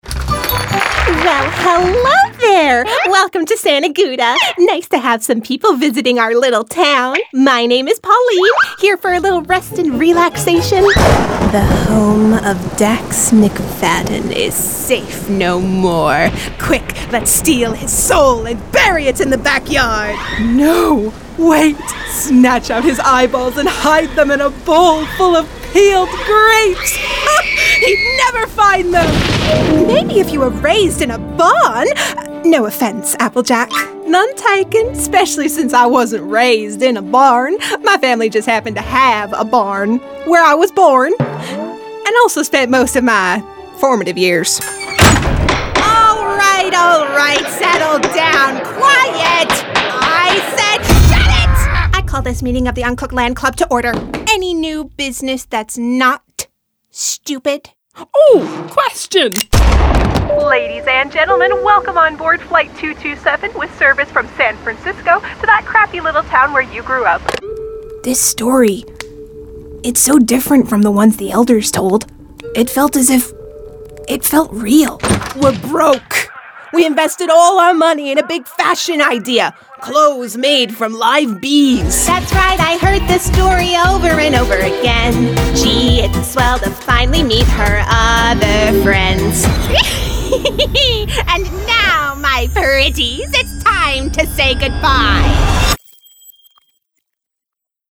Voice Acting